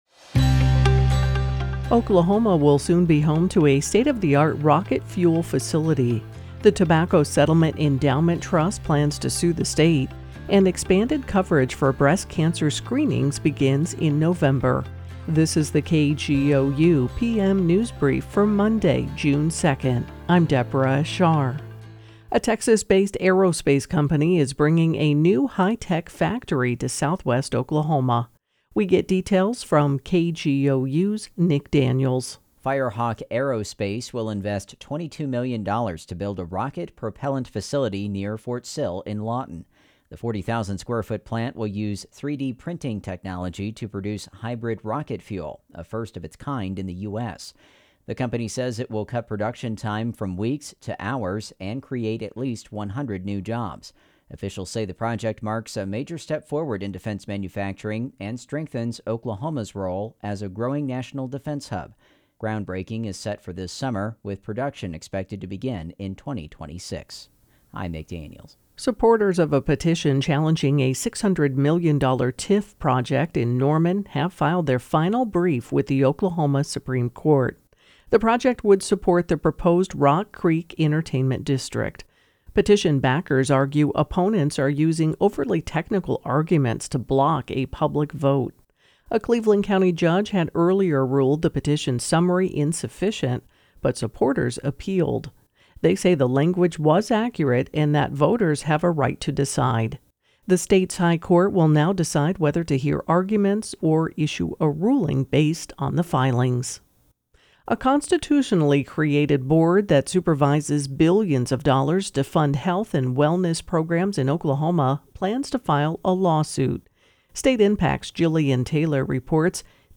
Latest Oklahoma news from KGOU - Your NPR Source … continue reading 194 Episoden # Daily News # Politics # News # KGOU # KGOU Radio